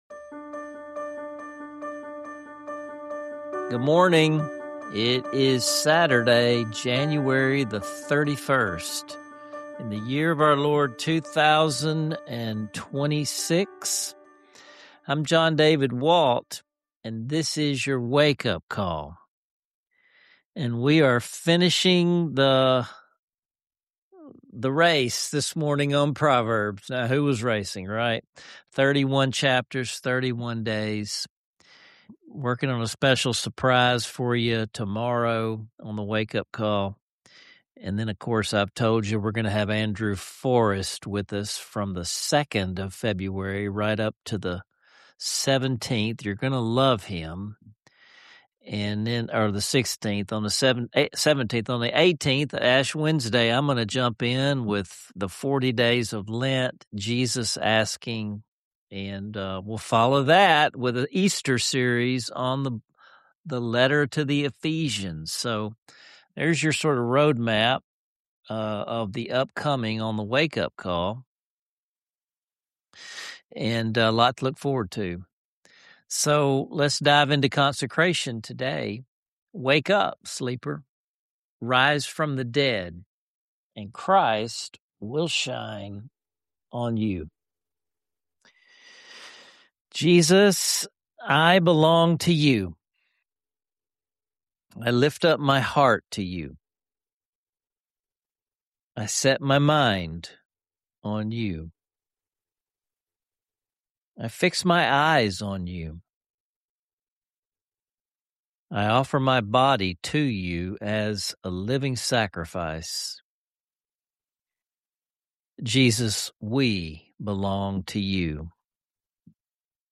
This episode wraps up with prayer, journal prompts, and a soul-lifting devotional song, making for an uplifting close to the Proverbs journey.